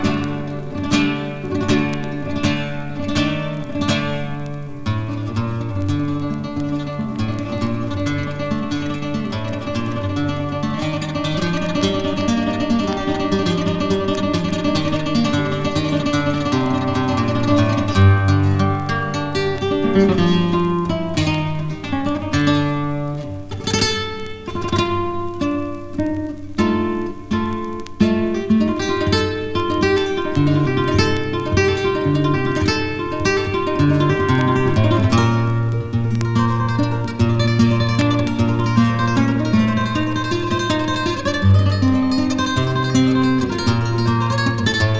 旅情とダイナミックさが楽しいスペイン編。
Popular, Classical, Jazz　USA　12inchレコード　33rpm　Mono